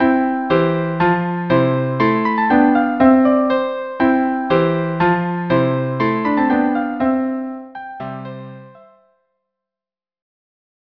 Instrumentation: Violin 1; Violin 2 or Viola; Cello